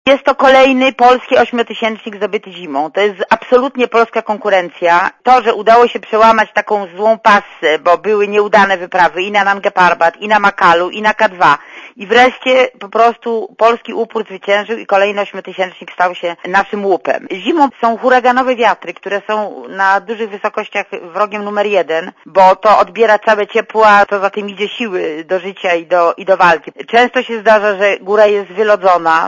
To ogromne osiągnięcie – powiedziała Radiu ZET himalaistka Anna Czerwińska.